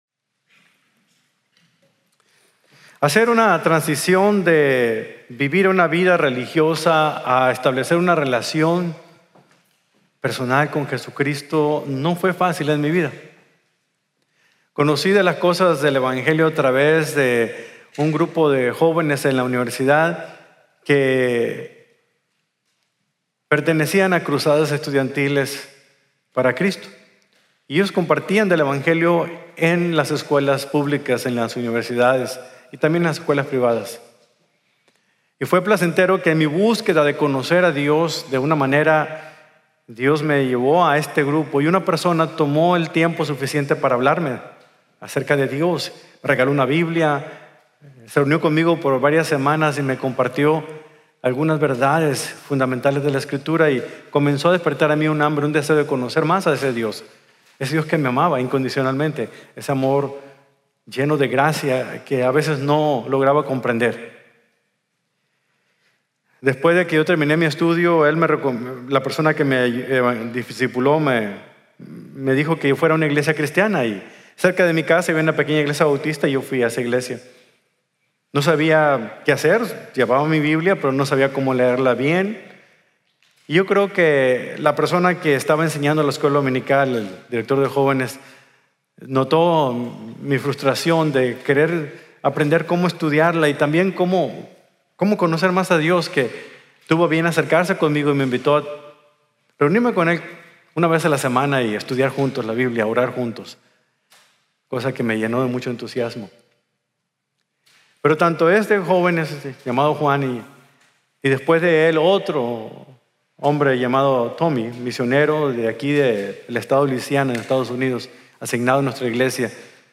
Encontrar y Seguir | Sermon | Grace Bible Church